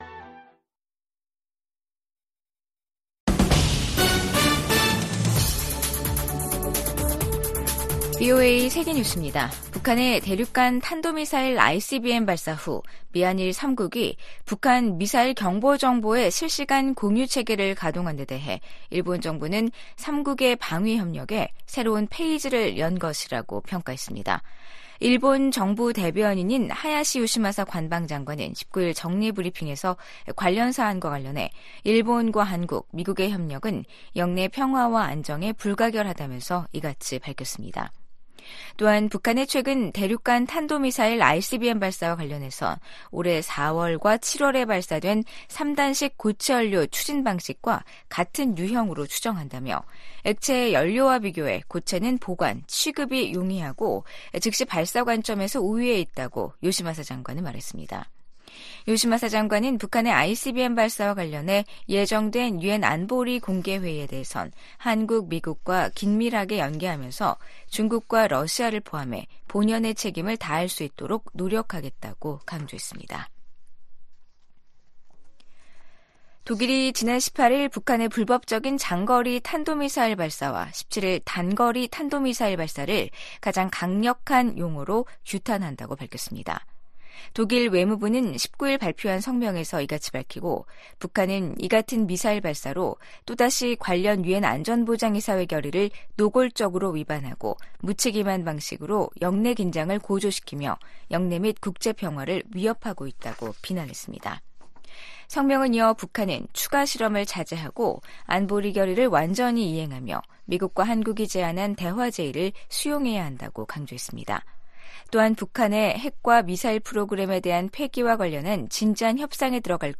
VOA 한국어 간판 뉴스 프로그램 '뉴스 투데이', 2023년 12월 19 일 2부 방송입니다. 유엔 안보리가 북한의 대륙간탄도미사일(ICBM) 발사에 대응한 긴급 공개회의를 개최합니다. 미 국무부는 중국에 북한의 개발 핵 야욕을 억제하도록 건설적 역할을 촉구했습니다. 북한은 어제(18일) 고체연료 기반의 대륙간탄도미사일 ‘화성-18형’ 발사 훈련을 실시했다며 미국 본토에 대한 핵 위협을 노골화했습니다.